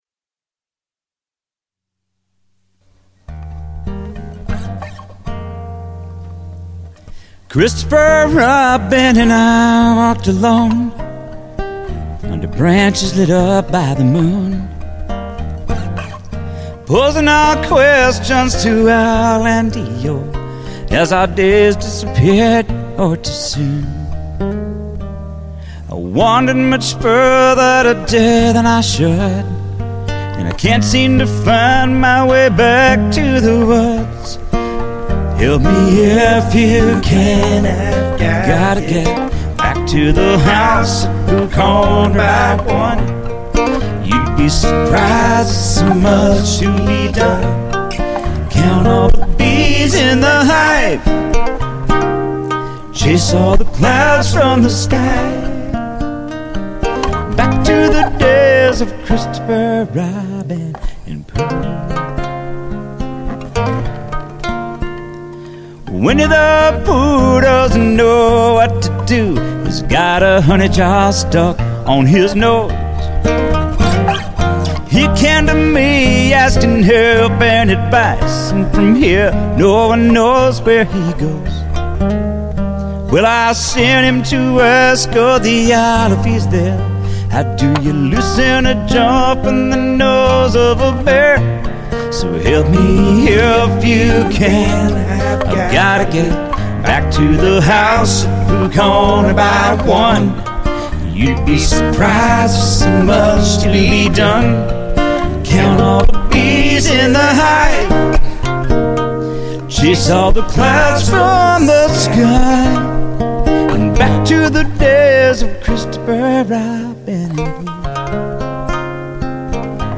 guitar. I have back up vocals and mandolin.